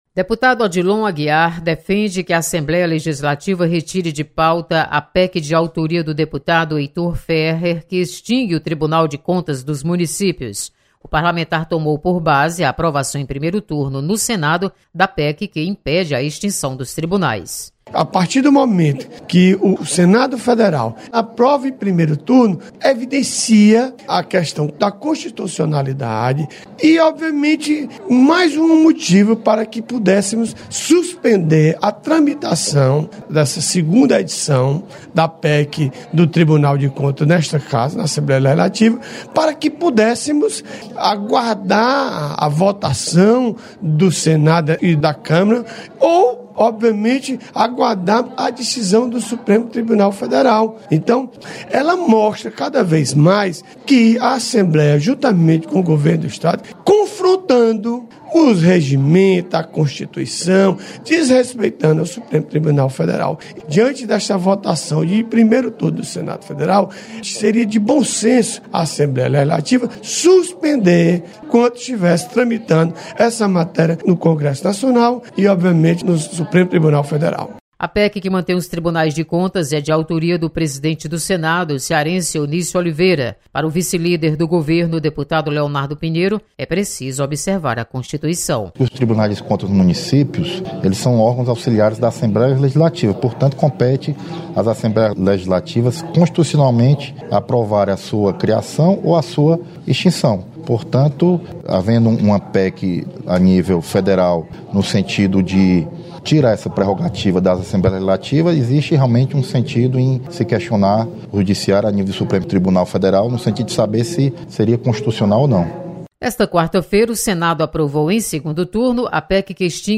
Deputados divergem quanto à tramitação de PEC que visa extinguir TCM. Repórter